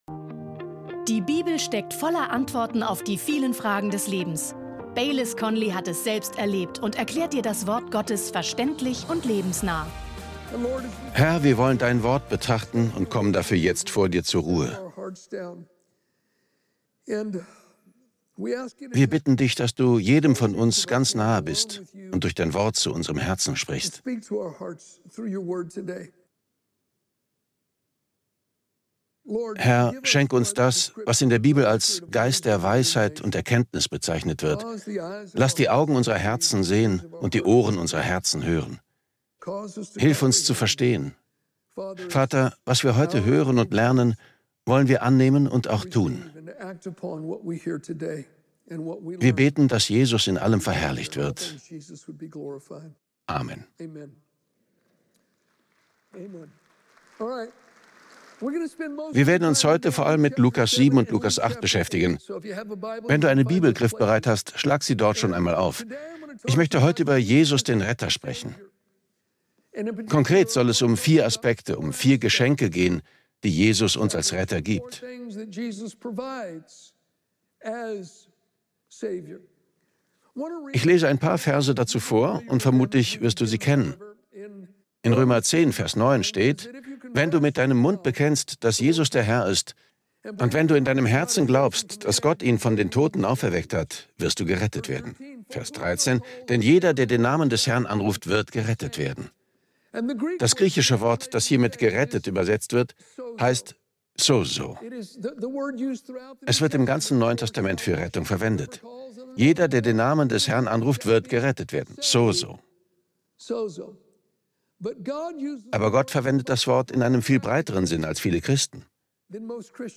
Beschreibung vor 8 Monaten Wusstest du, dass Gottes Rettung weit mehr bedeutet als nur die Vergebung deiner Sünden? In dieser Predigt